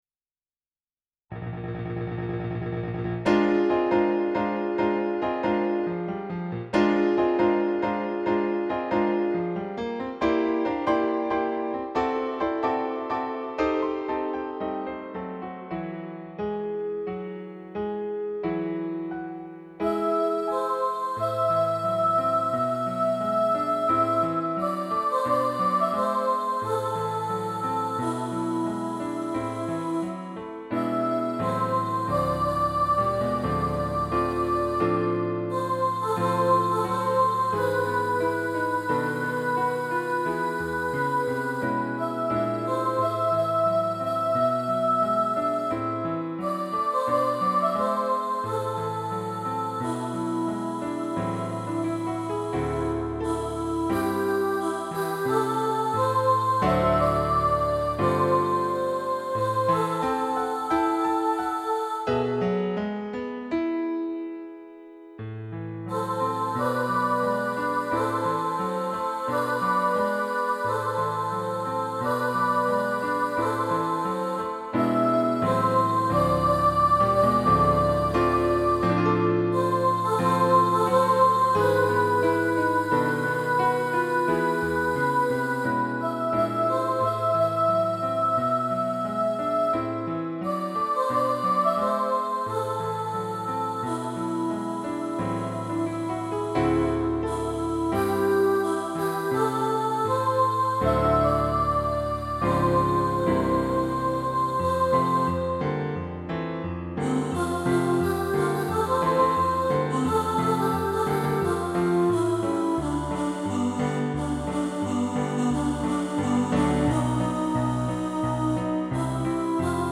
Porgy-And-Bess-Soprano.mp3